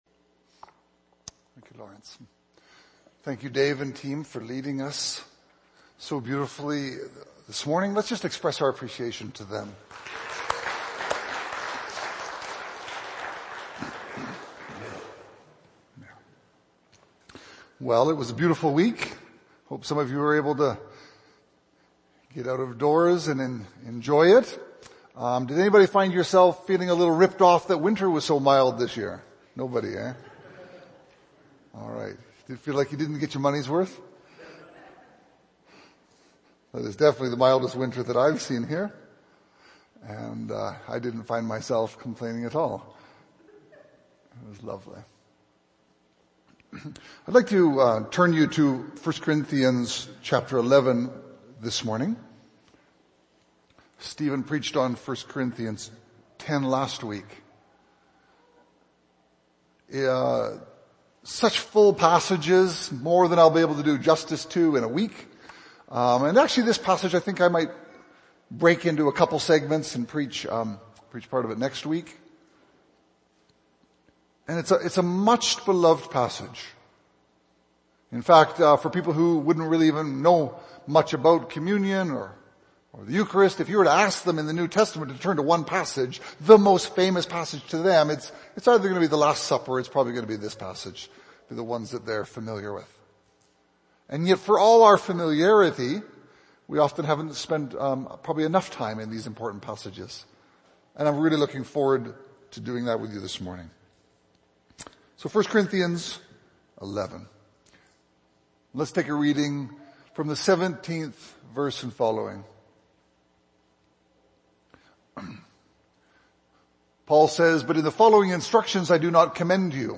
In this sermon, the speaker addresses the clash between the materialistic culture we live in and the teachings of the Lord. He emphasizes that our society is consumed with the accumulation of things, believing that more possessions will bring happiness.